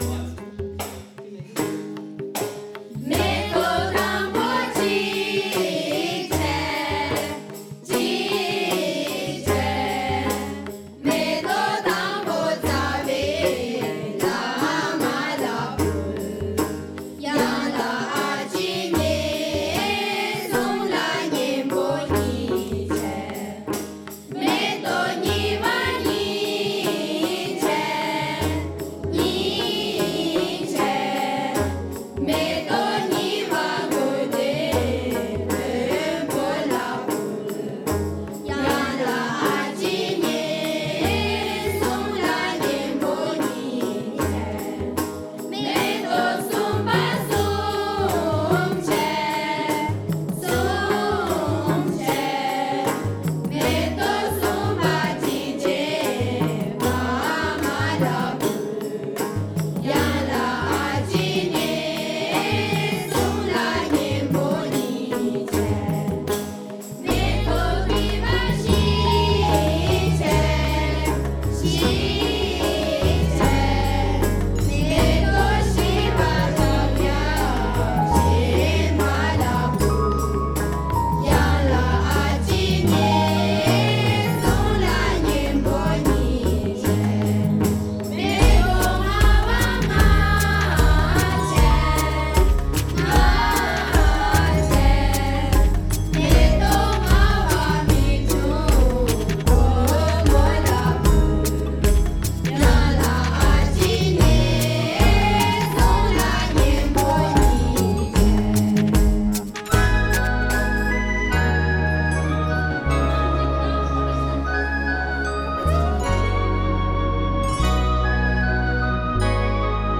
Metok: Traditional Dolpo song about a flower, with a modern arrangement